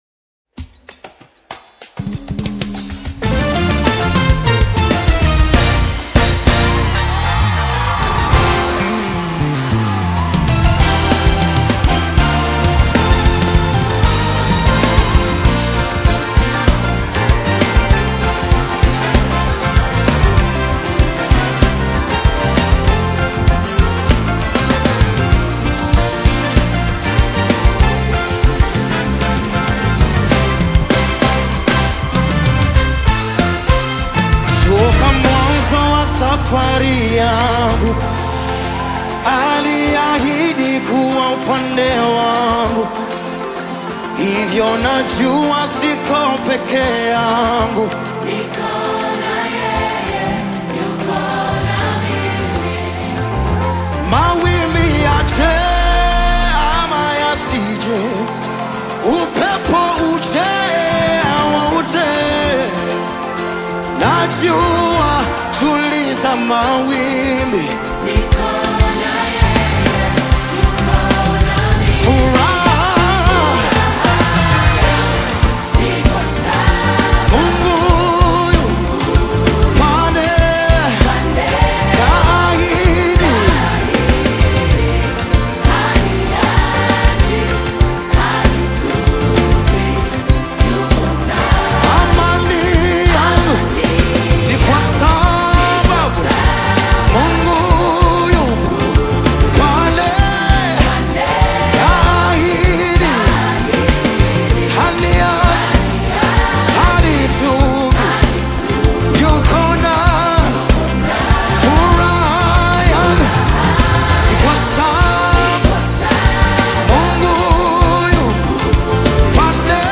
The breathtakingly cinematic and Spirit-led new single
signature soaring vocals
a production that feels like a film score for the soul